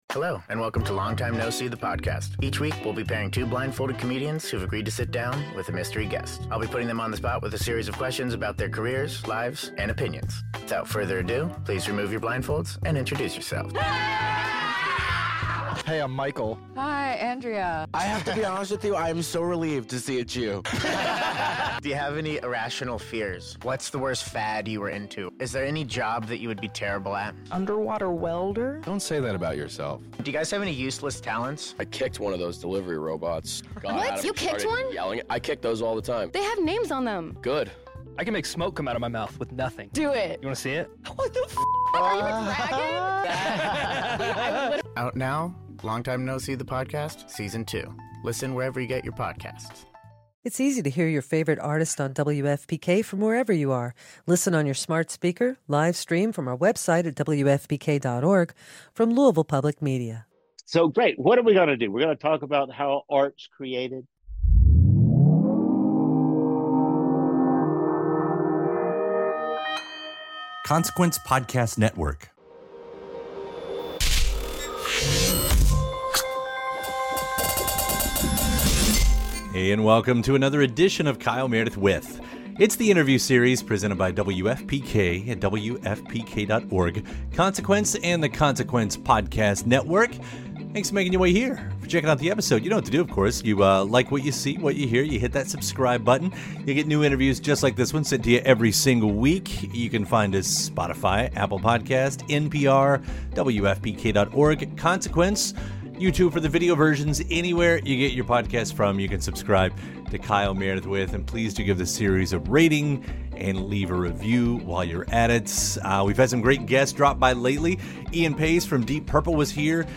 an interview series